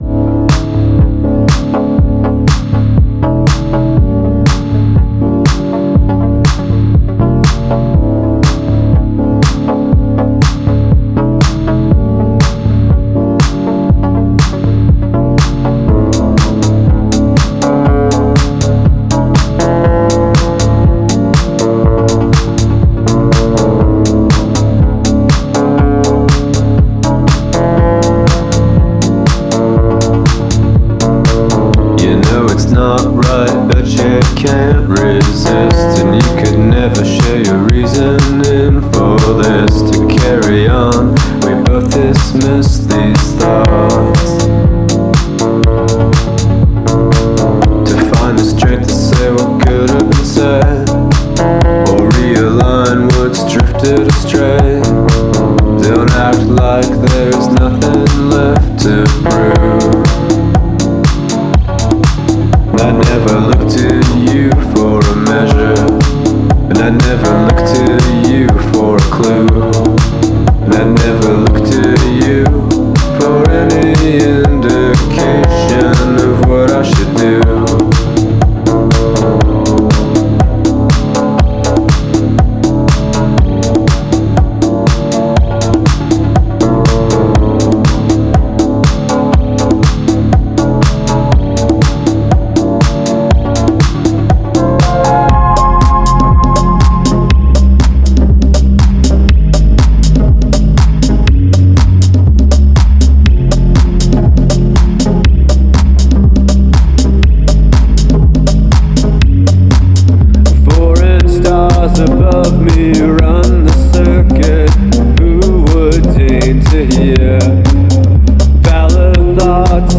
Genre: Coldwave.